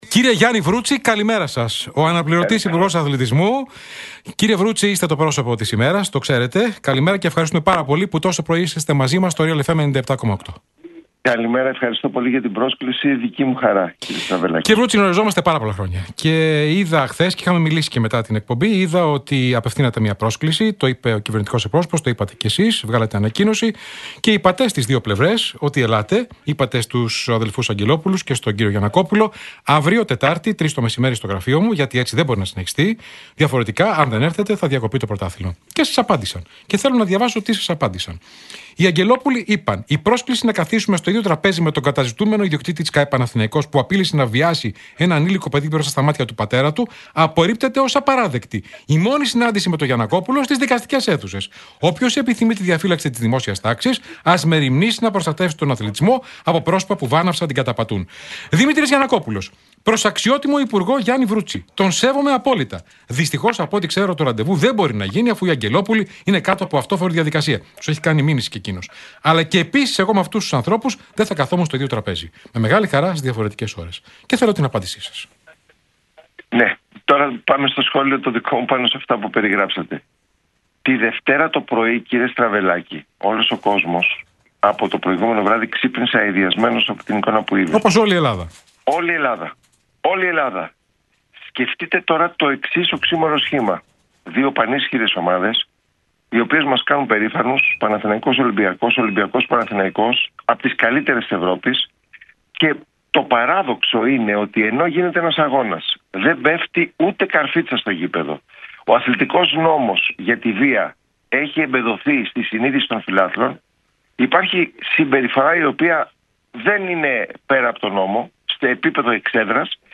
Νέο μήνυμα έστειλε ο αναπληρωτής υπουργός Αθλητισμού, Γιάννης Βρούτσης, μέσω του Realfm 97,8 στους ιδιοκτήτες των ΚΑΕ Παναθηναϊκού και Ολυμπιακού.